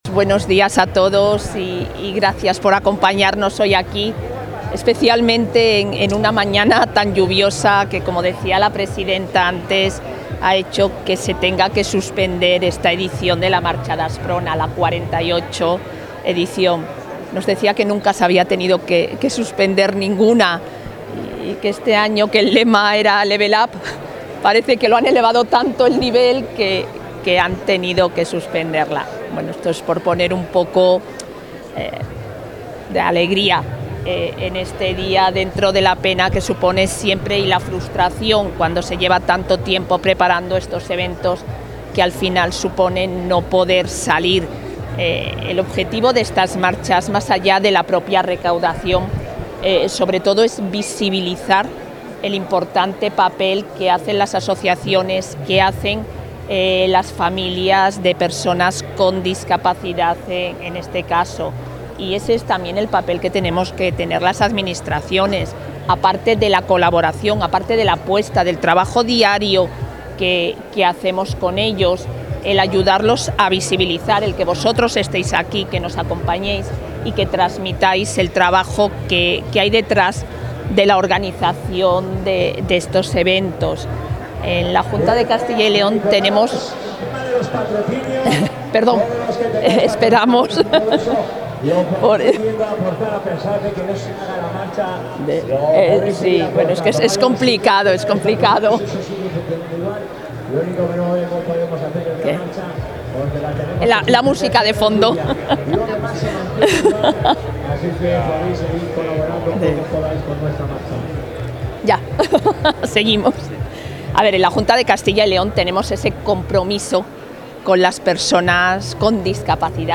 Declaraciones de la vicepresidenta.
La vicepresidenta y consejera de Familia e Igualdad de Oportunidades ha estado presente en la salida de la 48 º Marcha Asprona, que se ha suspendido por la lluvia, donde ha enfatizado el compromiso firme y sin fisuras de la Junta con las personas con discapacidad a través del incremento y la mejora continuos de servicios y recursos, y su apoyo al colectivo mediante la apuesta por el empleo o el asistente personal.